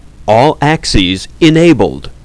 Speech Messages
Our controls also give speech messages during certain conditions or events on the bender.
Click here to hear the "All Axes Enabled" speech message. This message is played when the bender axes are enabled and ready to move.